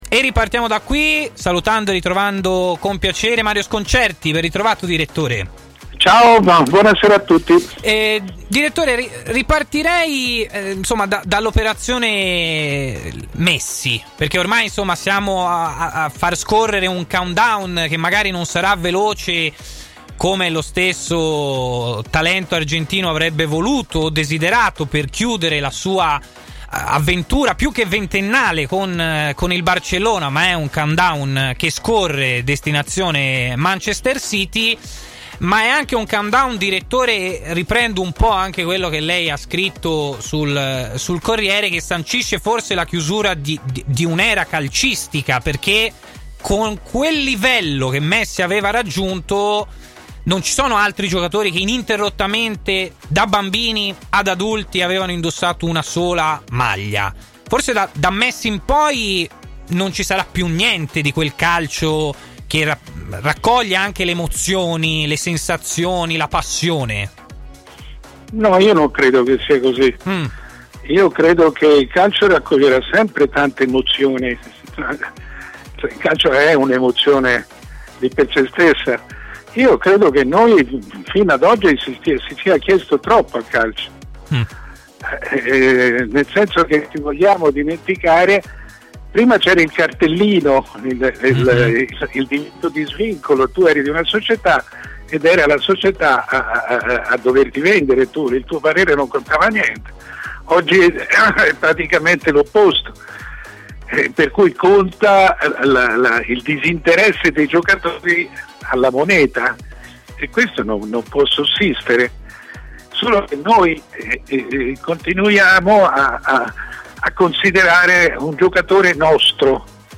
Sul futuro di Lionel Messi è intervenuto ai microfoni di Tmw il direttore Mario Sconcerti.